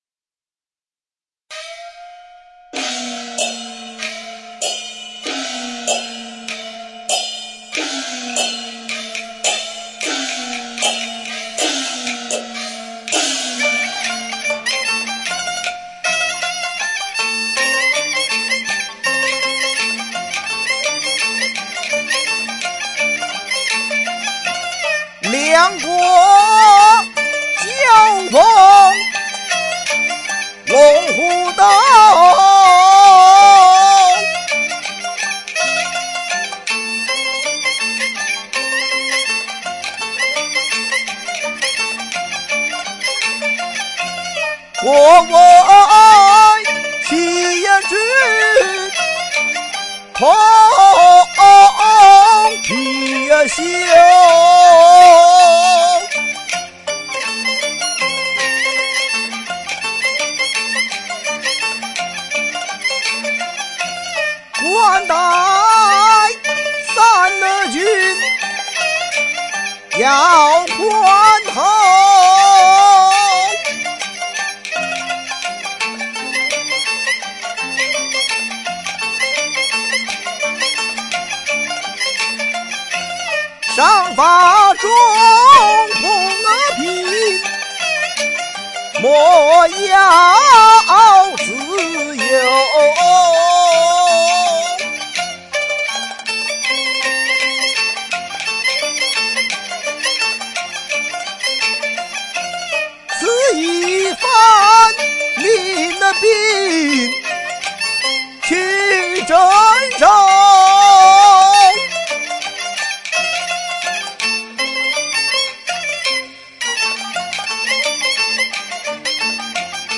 京剧